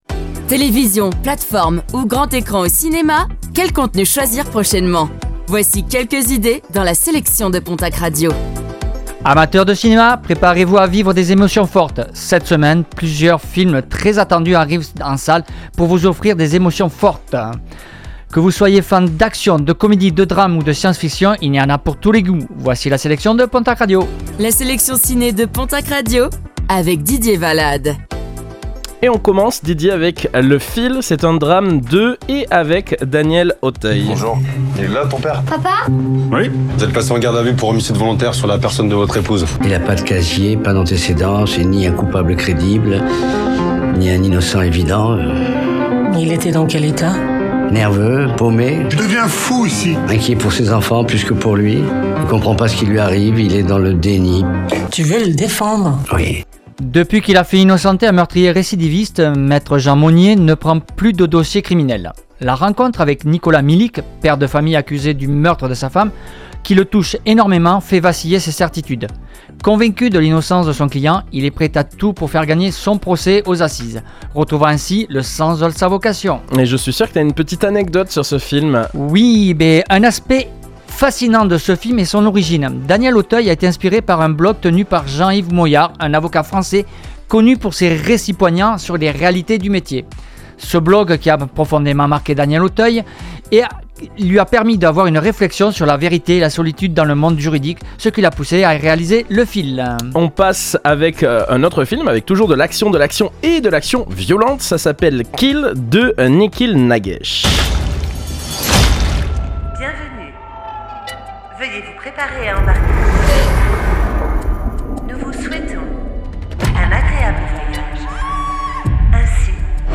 Chronique ciné-séries - Mercredi 11 septembre 2024